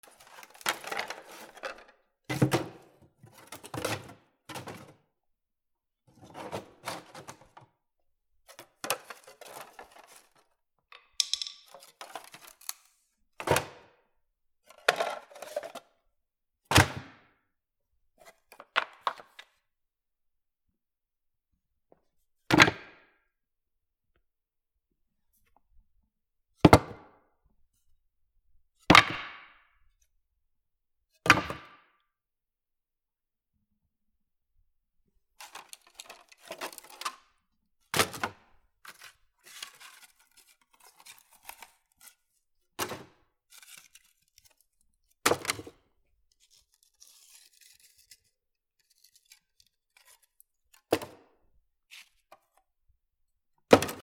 / M｜他分類 / L01 ｜小道具 /
木のゴミをゴミ箱に入れる
『ゴトン』